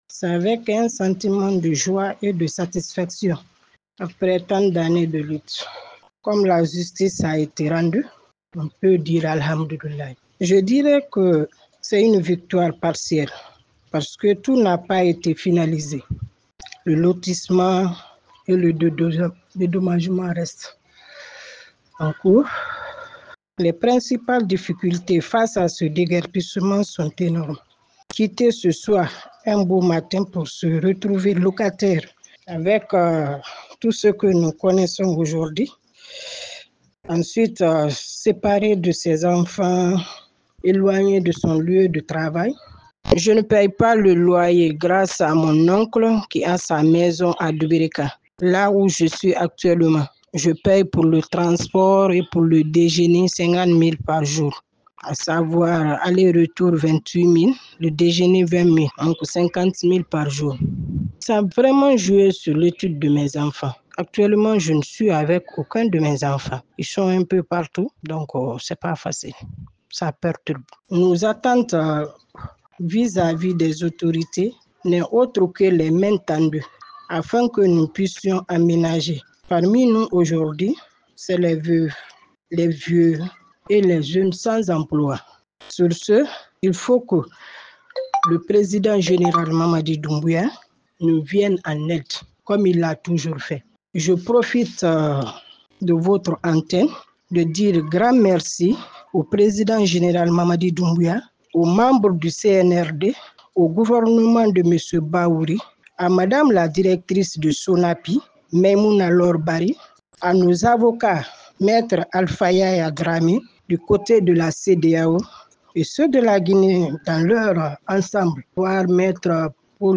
témoignage émouvant